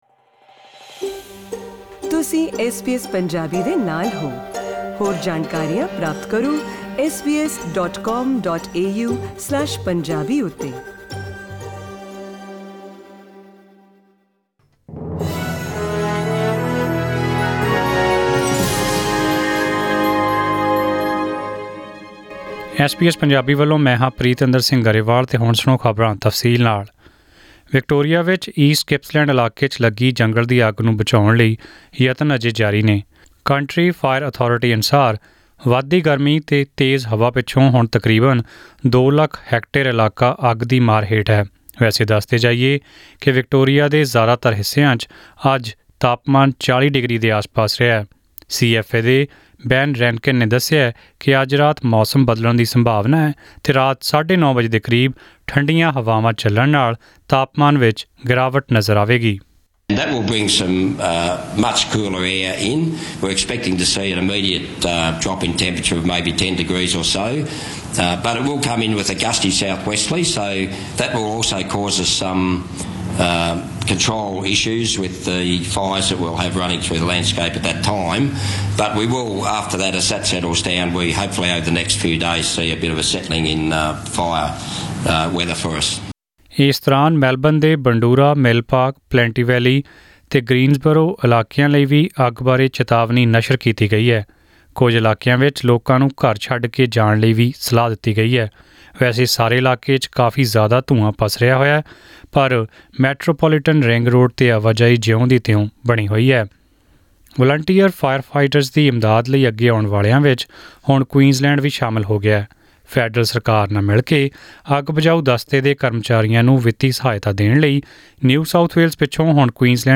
In today’s news bulletin -